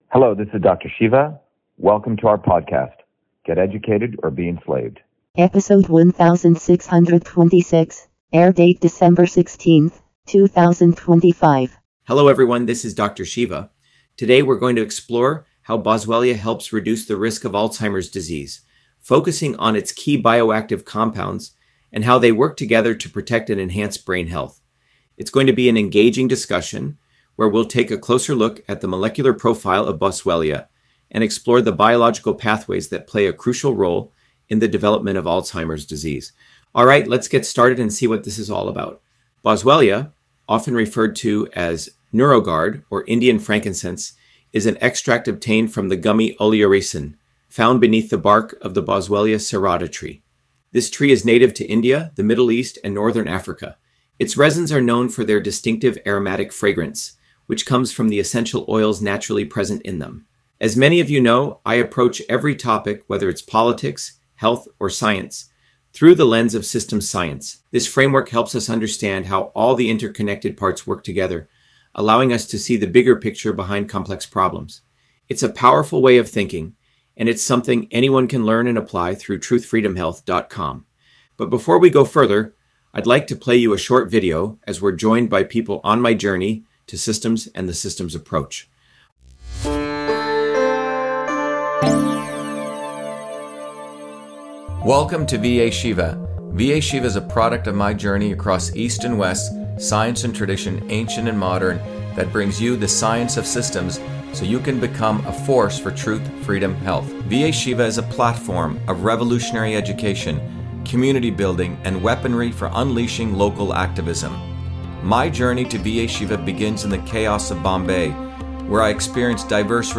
In this interview, Dr.SHIVA Ayyadurai, MIT PhD, Inventor of Email, Scientist, Engineer and Candidate for President, Talks about Boswellia serrata on Alzheimer’s Disease